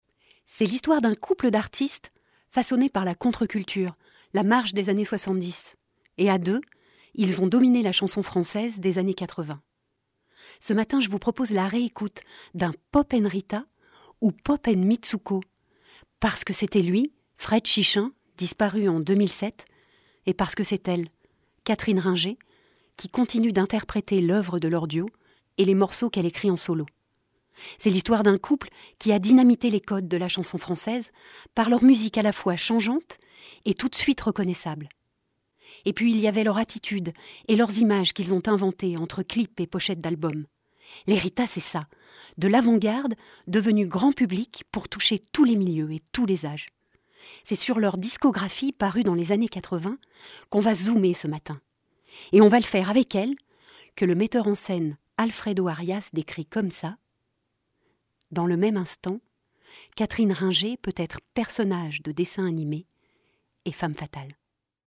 Voix off
voix radio
- Soprano